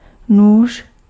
Ääntäminen
Synonyymit kudla Ääntäminen : IPA: [nuːʃ] Haettu sana löytyi näillä lähdekielillä: tšekki Käännös Ääninäyte Substantiivit 1. knife US UK Suku: m .